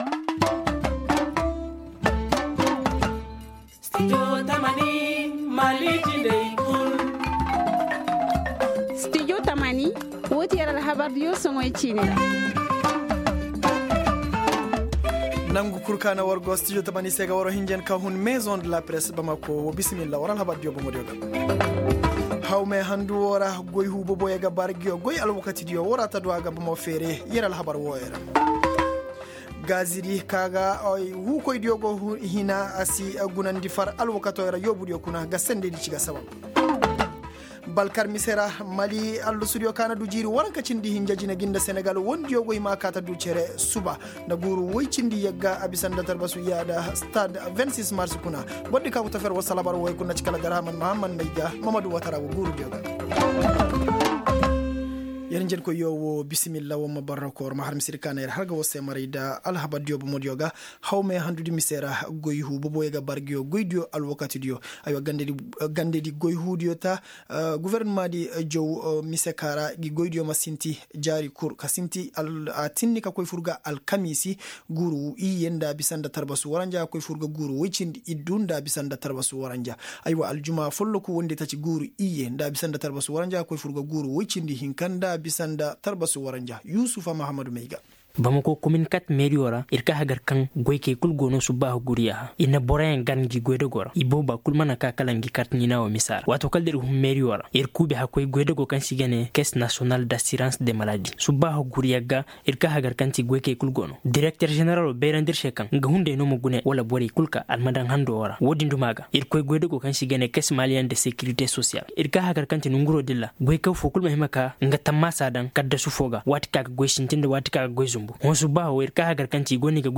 Comment les employés s’adaptent à cette situation ? La réponse dans ce journal.